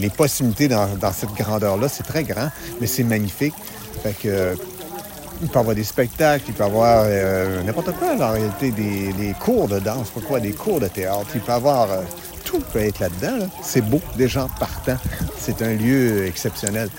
C’est ce qui a été dévoilé en conférence de presse lundi avant-midi.
Le maire, Jean-Guy Beaudet, a mentionné que cet aide changera le visage de la bâtisse.